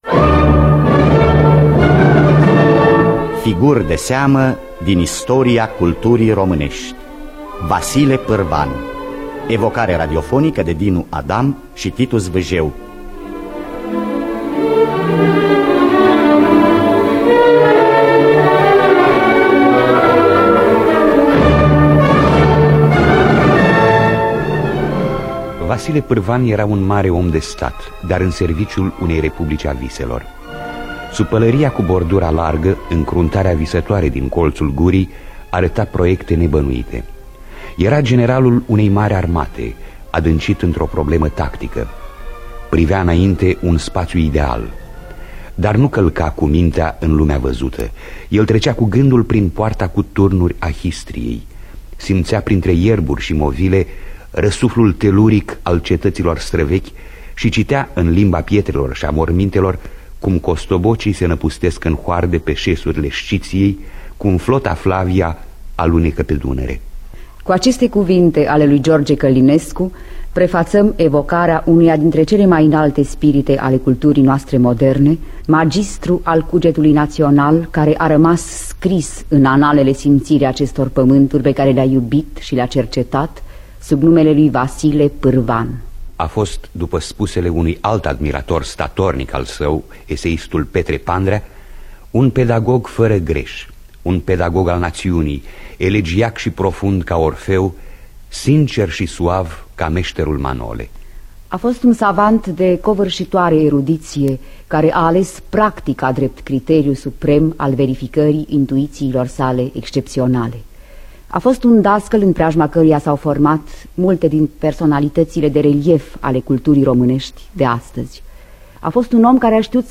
Biografii, Memorii: Vasile Parvan (1977) – Teatru Radiofonic Online